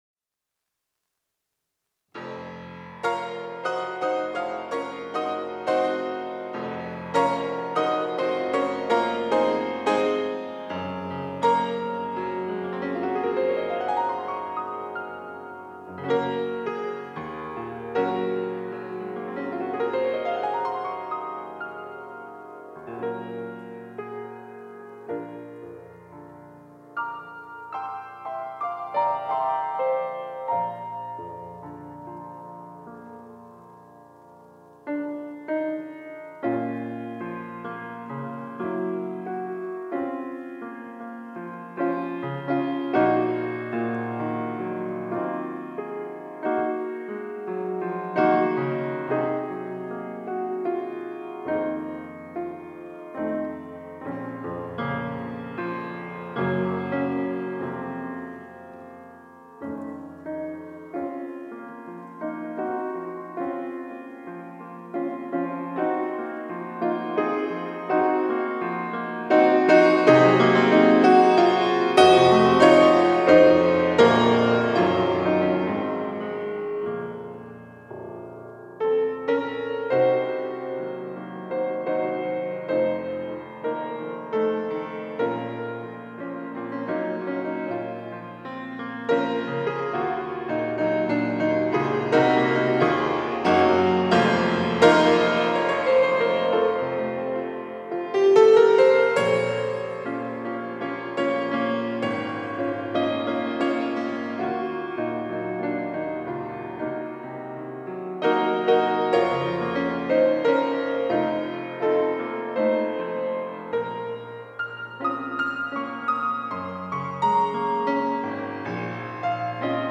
특송과 특주 - 갈보리산 위에